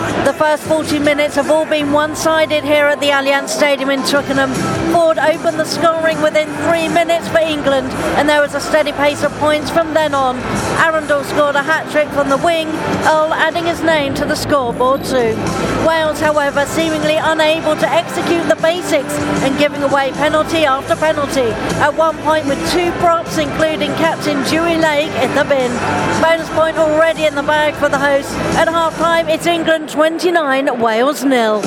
reports on the first 40-minutes of England's opening game in the 2026 Six Nations Championship. The hosts dominating and preventing visitors Wales from responding: